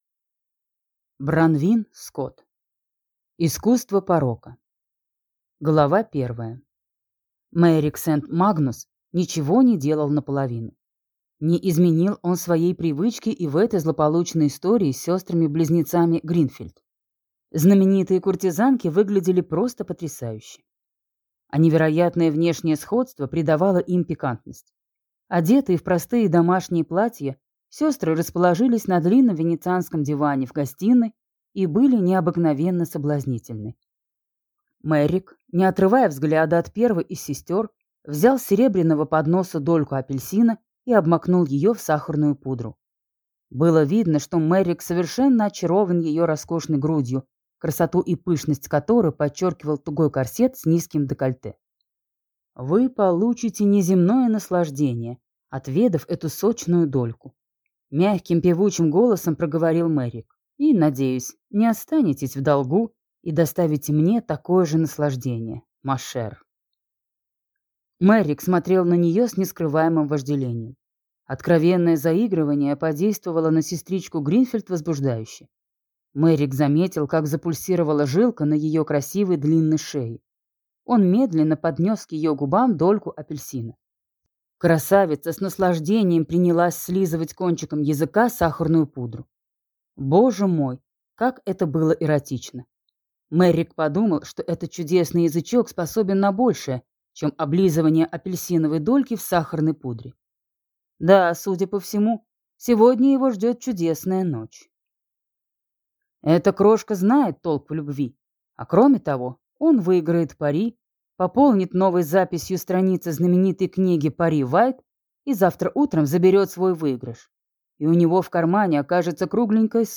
Аудиокнига Искусство порока | Библиотека аудиокниг